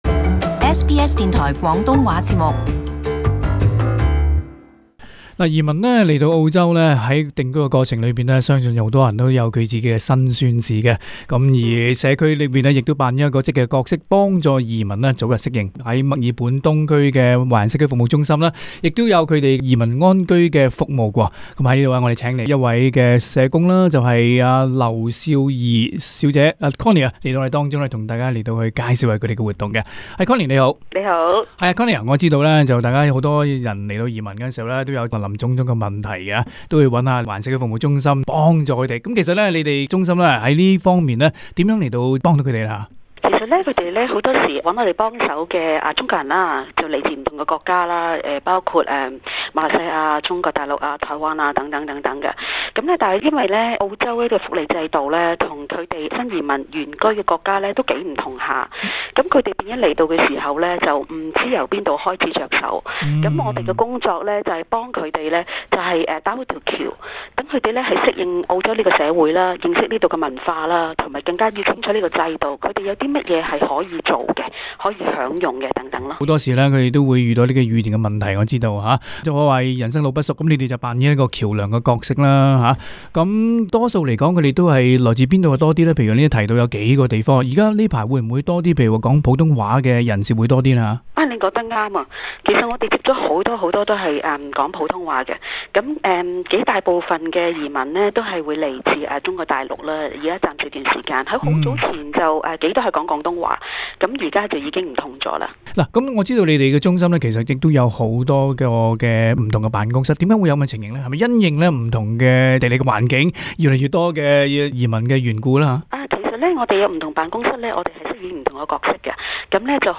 【社團專訪】華人社區中心移民資訊周活動